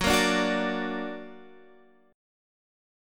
Gb+ Chord
Listen to Gb+ strummed